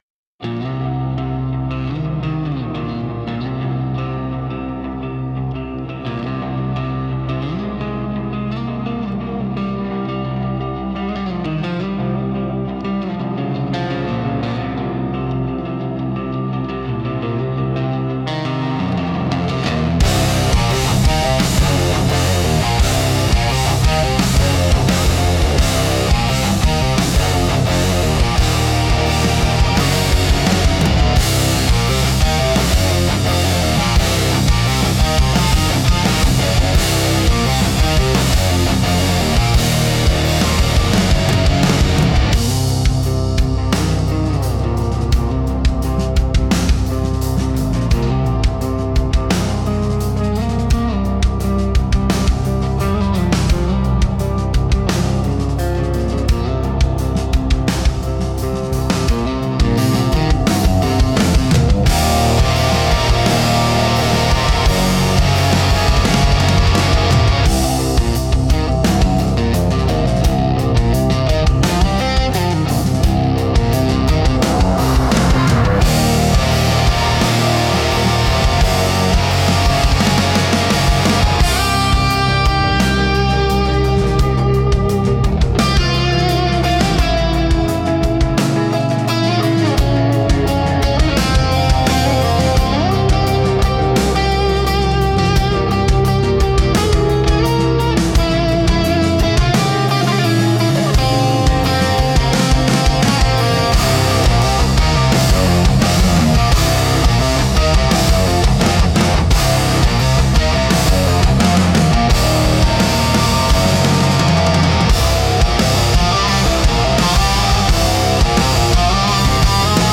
Instrumental - Frayed End of Intention